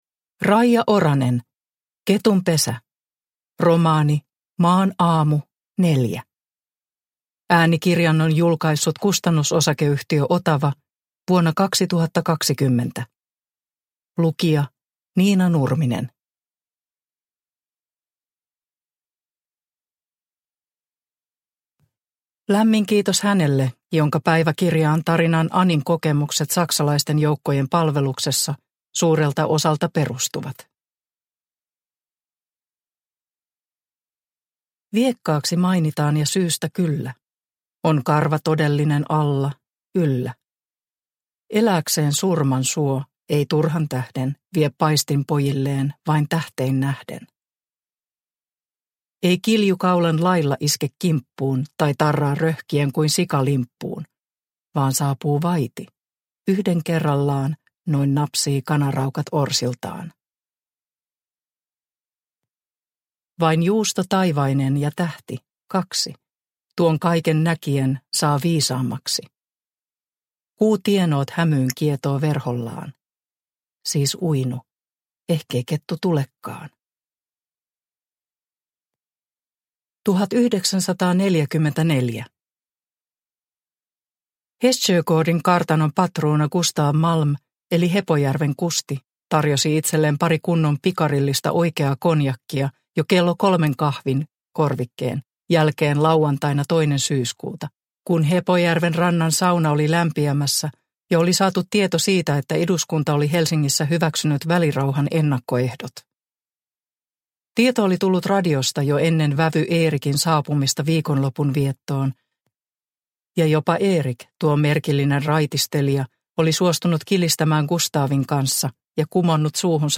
Ketunpesä – Ljudbok – Laddas ner